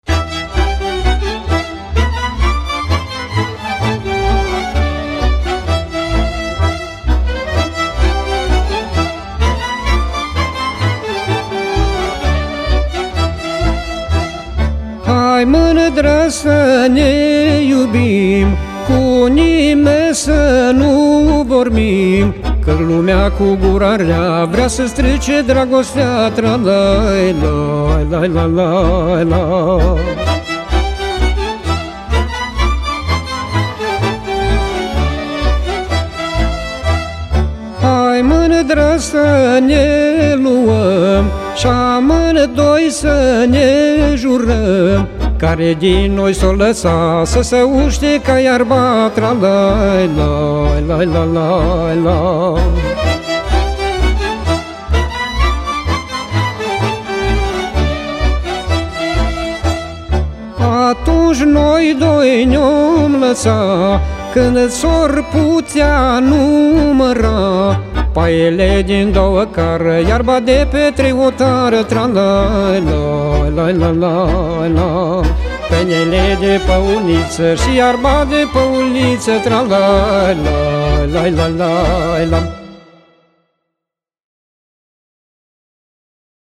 Acompaniamentul cântecelor de pe acest compact disc a fost asigurat de către Orchestra profesionistă